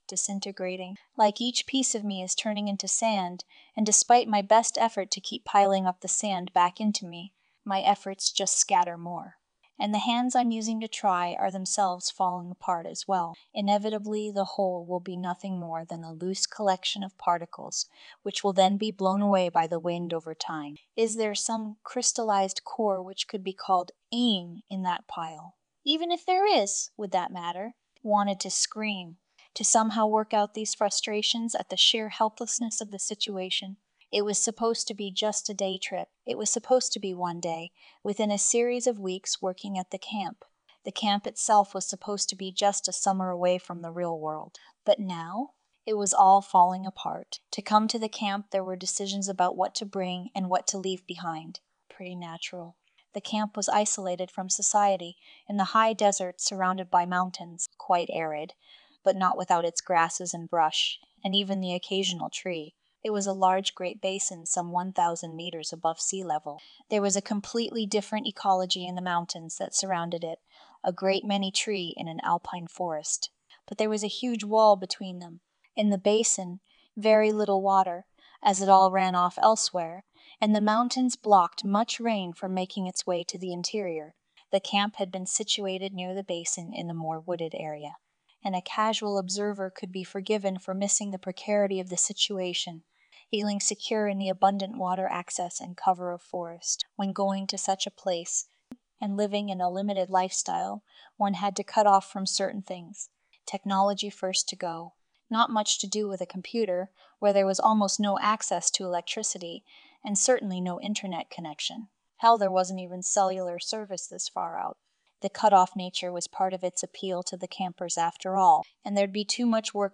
ambient red safe utility writing